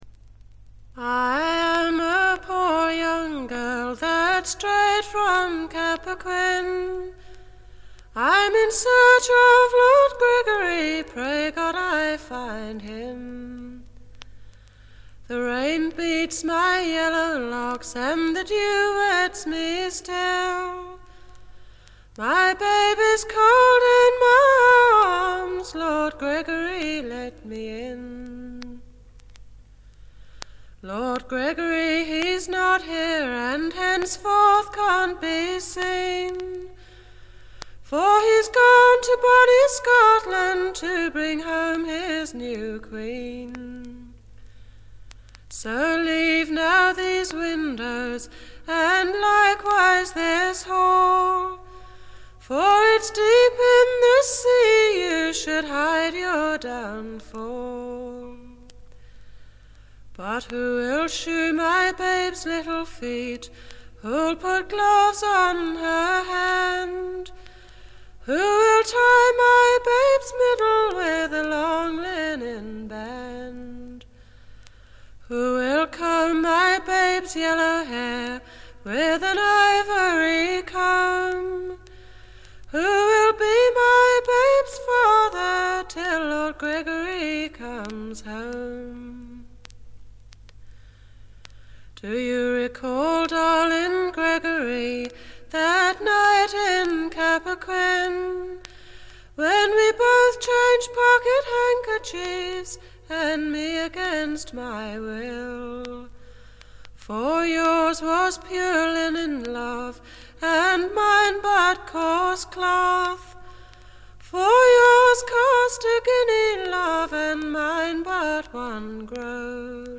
バックグラウンドノイズ、軽微なチリプチが聞かれはしますがほとんど気にならないレベルと思います。
試聴曲は現品からの取り込み音源です。